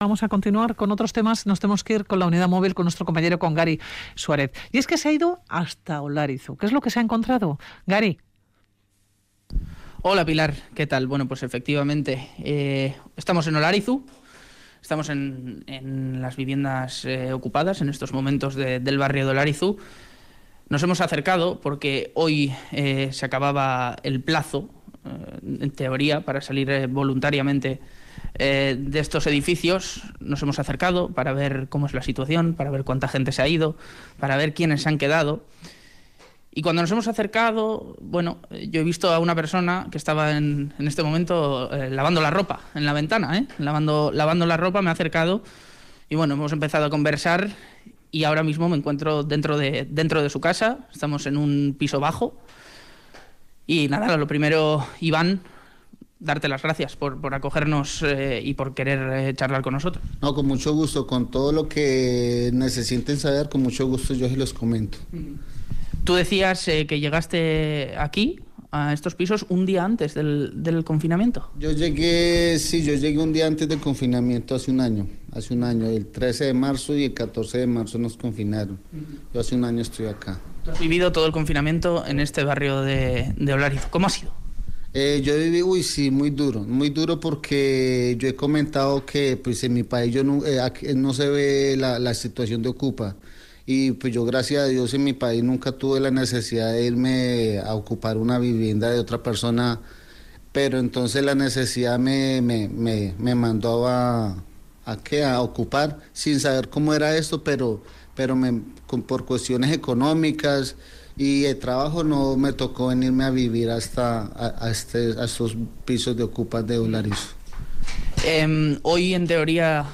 Radio Vitoria entra en uno de los pisos ocupados de Olarizu